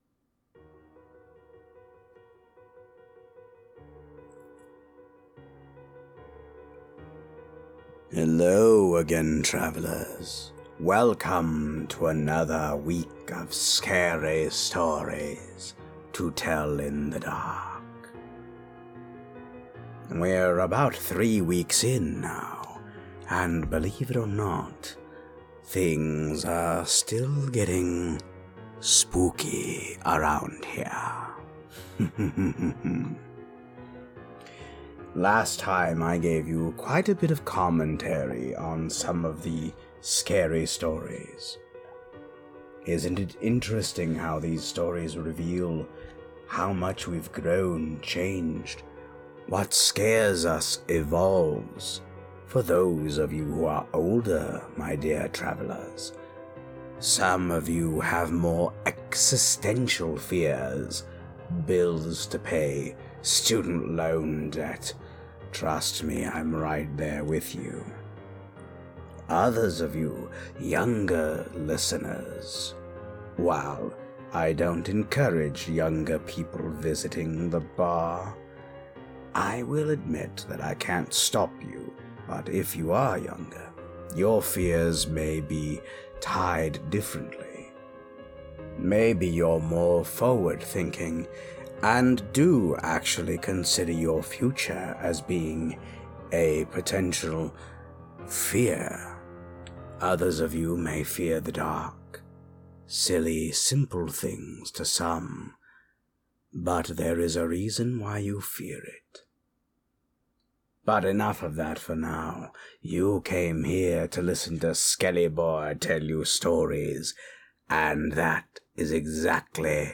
Stories Read: Wait Till Martin Comes, The Dead Man's Hand, Just Delicious,